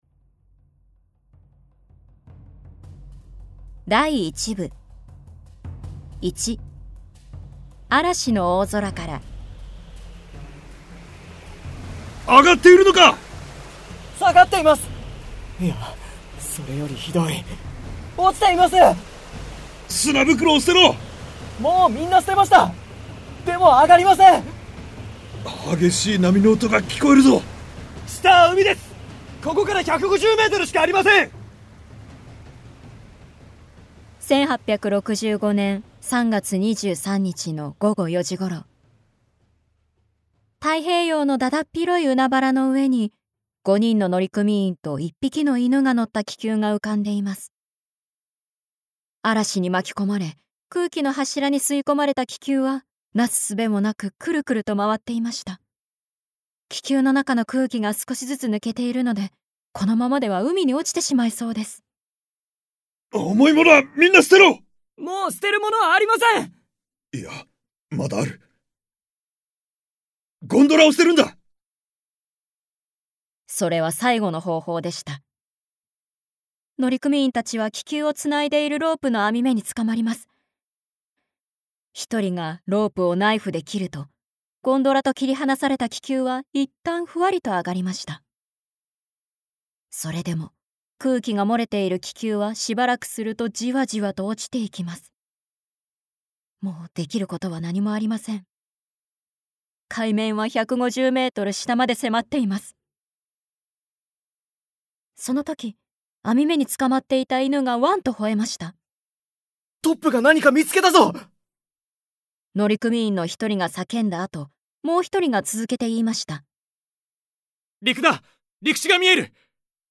[オーディオブック] 神秘の島（こどものための聴く名作42）